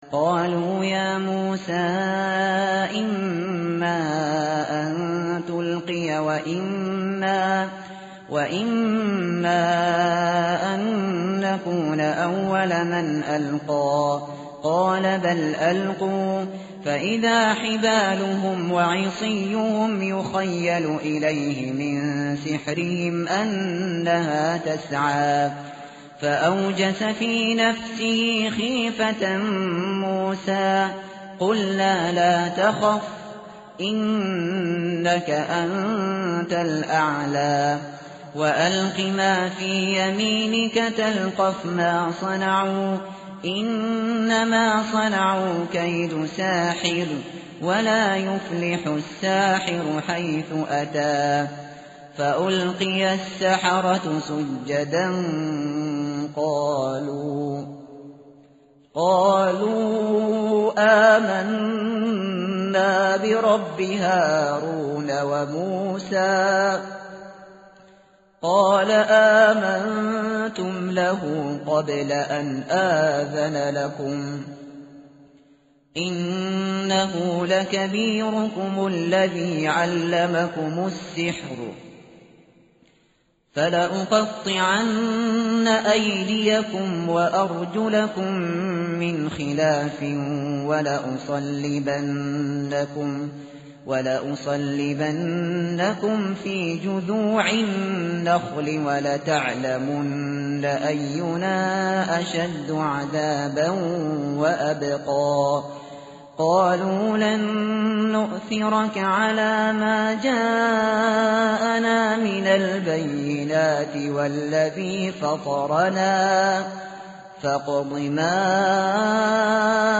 tartil_shateri_page_316.mp3